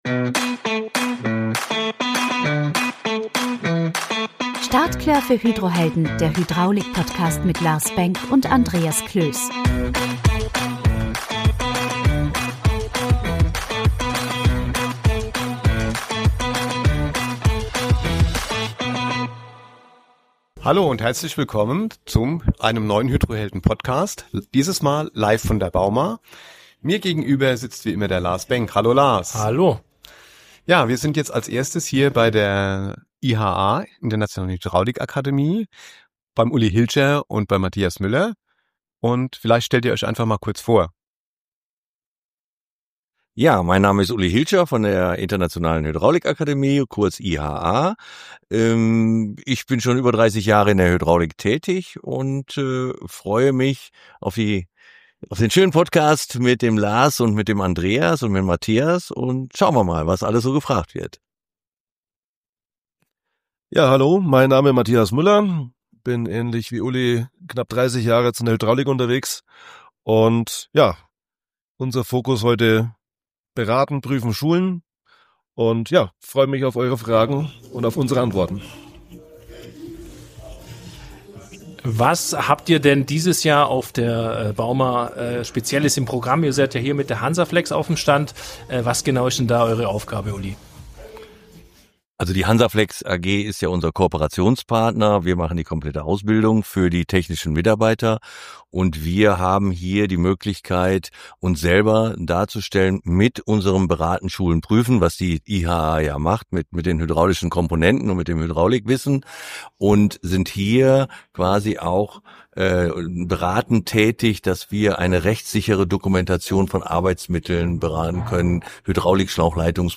Podcast live von der Bauma – Stimmen aus der Hydraulikbranche In dieser Sonderfolge direkt von der Bauma erwarten sie spannende Gespräche mit führenden Experten der Hydraulikbranche.